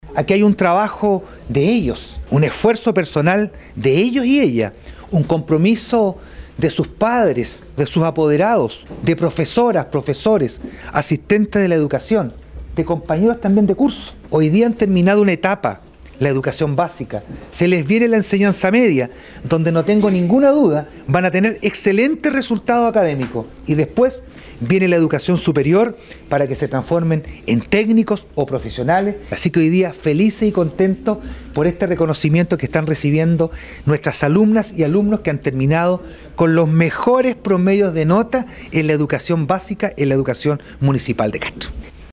En la instancia que tuvo lugar en la sala de sesiones del municipio, el jefe comunal quiso conocer más a los estudiantes y reconocer y premiar su esfuerzo y desempeño destacado.
El alcalde Vera, como también lo hizo recientemente con los mejores promedios de 4° medios, le entregó a cada estudiante un obsequio como muestra de reconocimiento por su destacado desempeño académico y destacó el loro obtenido: